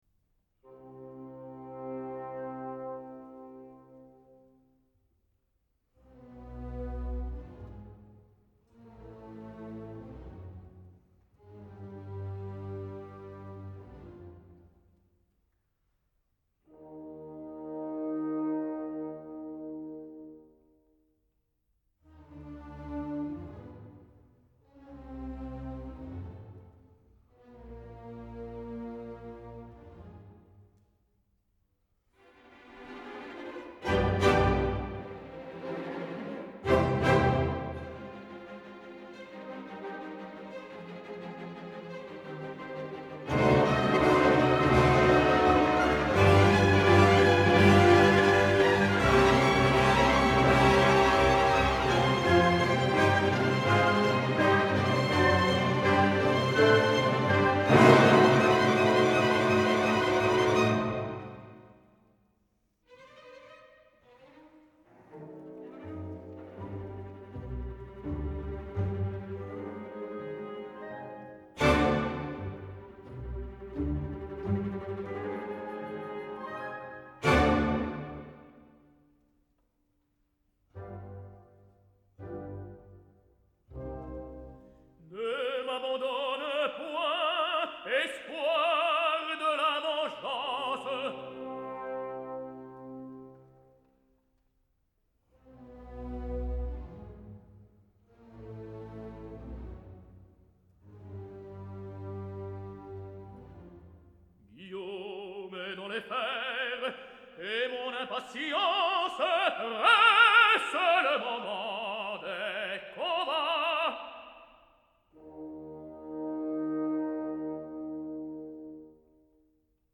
Роберто Аланья. Ария Арнольда из оперы Россини "Вильгельм Телль"